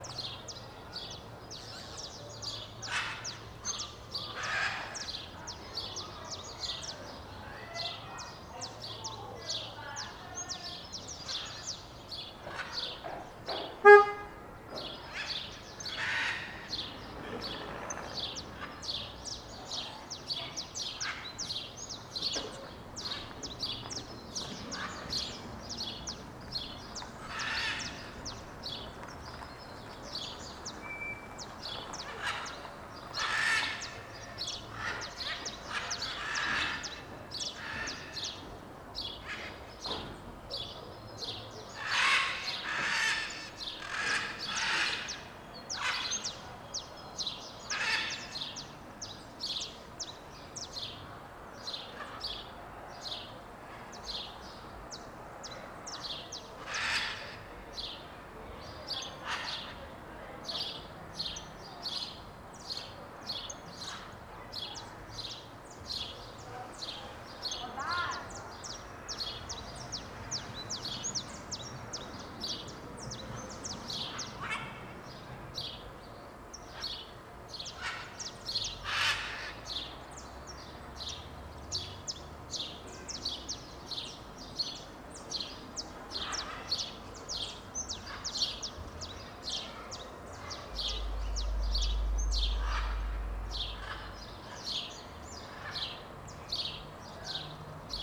CSC-04-274-OL- Ambiente diurno em ruas de Alto Paraiso.wav